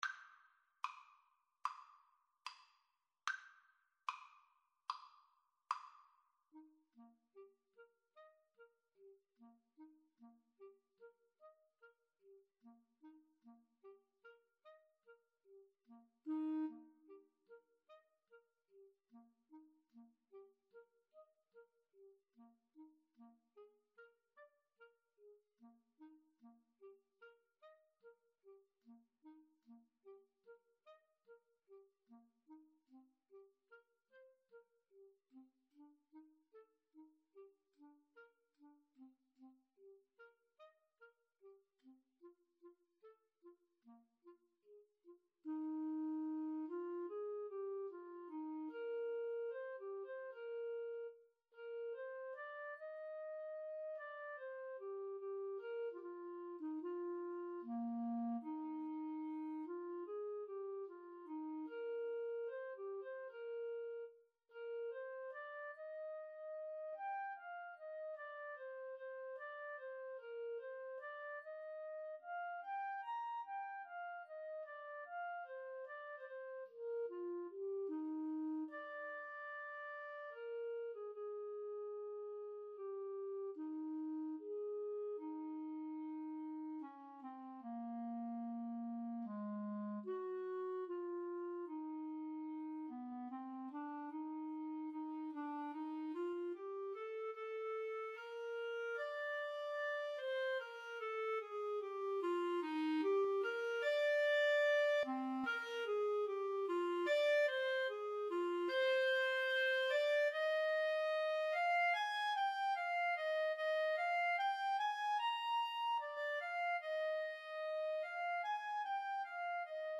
Eb major (Sounding Pitch) F major (Clarinet in Bb) (View more Eb major Music for Clarinet Duet )
Andantino quasi allegretto ( = 74) (View more music marked Andantino)
Classical (View more Classical Clarinet Duet Music)